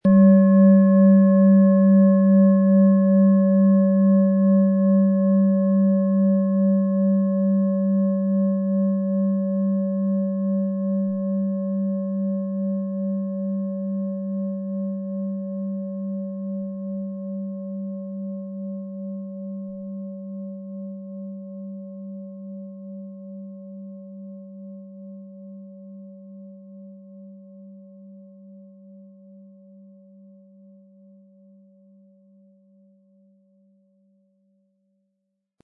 Planetenschale® antik Verbinde Dich zur Erde und Natur & Bewege Dich frei im Fluß der Veränderung mit Tageston & Pluto, Ø 18 cm, 500-600 Gramm inkl. Klöppel
Planetenton 1 Planetenton 2
Der Grundton - der Tageston - wirkt wie eine liebevolle Einladung, wieder im eigenen Körper anzukommen.
• Mittlerer Ton - Pluto: Pluto steht für Transformation und tiefgreifende Wandlung.
Und ihr Klang - oft tiefer und resonanter - erreicht Ebenen, die moderne Schalen selten ansprechen.
Im Audio-Player - Jetzt reinhören hören Sie genau den Original-Ton der angebotenen Schale.